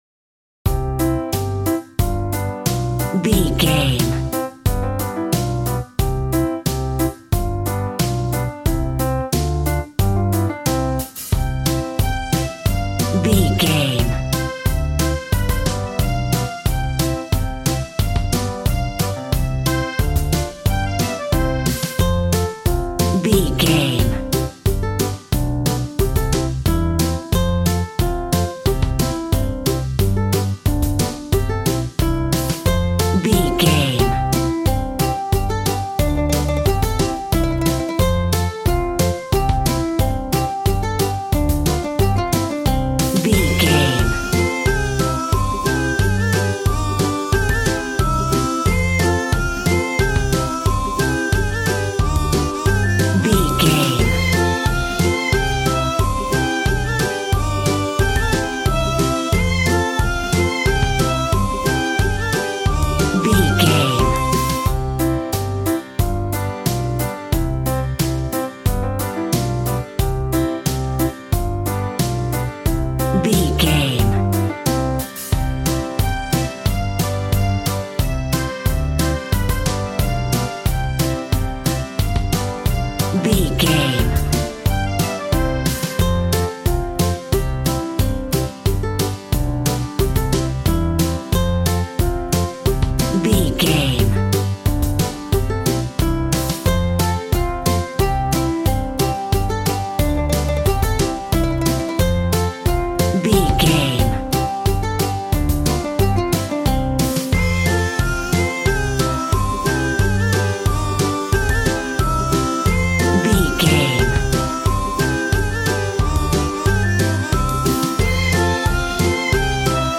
A cute and bubbly piece of kids music.
Upbeat, uptempo and exciting!
Ionian/Major
cheerful/happy
bouncy
electric piano
electric guitar
drum machine